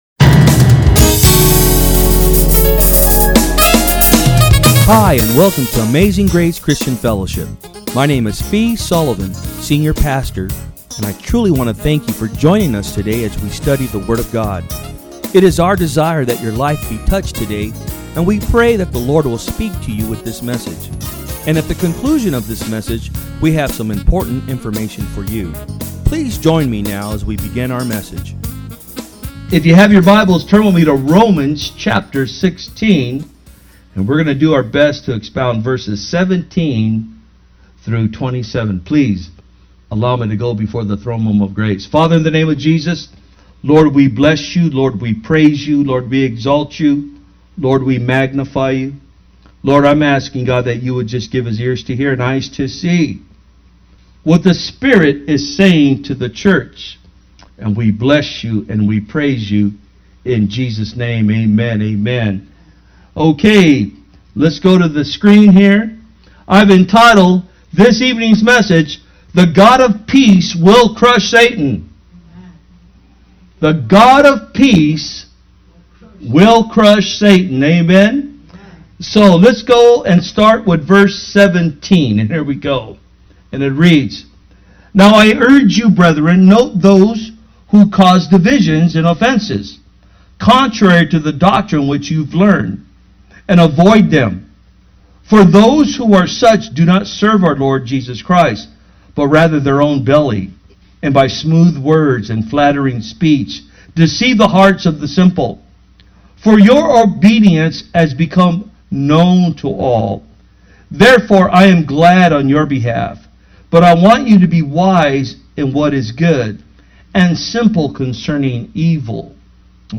Sermons
From Service: "Wednesday Pm"